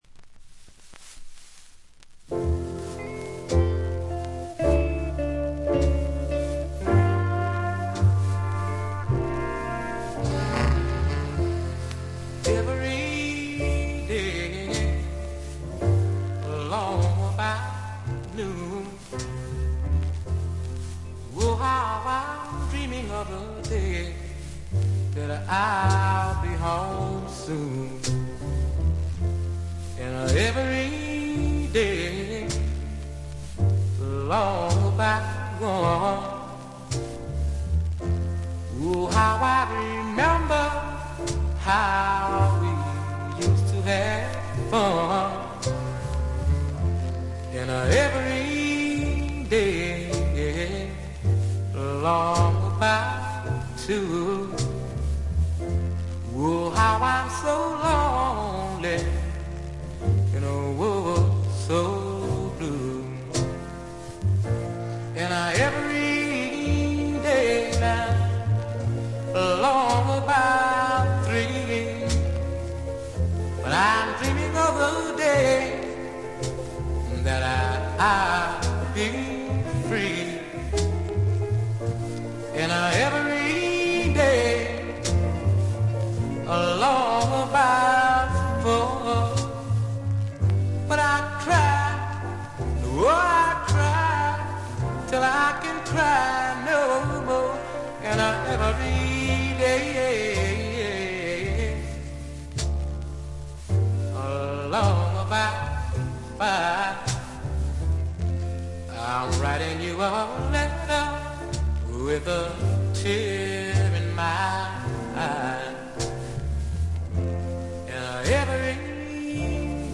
「くもり」のためか全体に軽微なバックグラウンドノイズが出ますが鮮度は良好です。ところどころでチリプチ。
試聴曲は現品からの取り込み音源です。